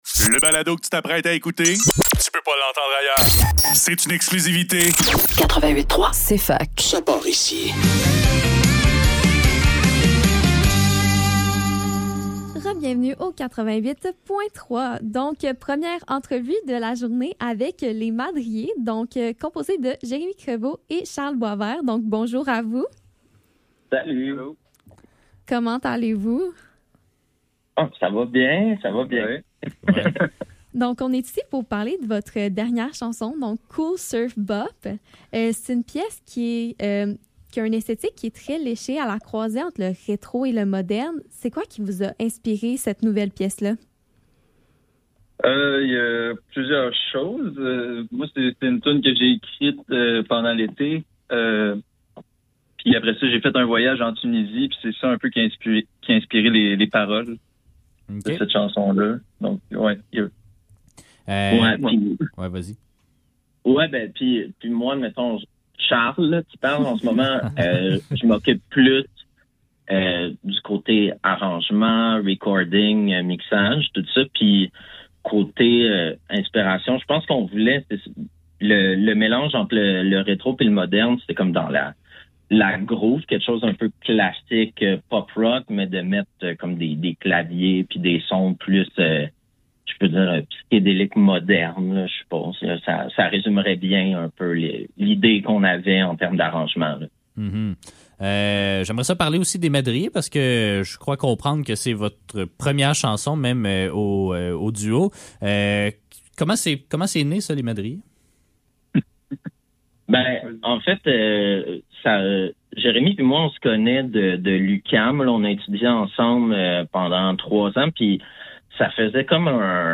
Cfaktuel - Entrevue : Les Madriers - 12 Juin 2025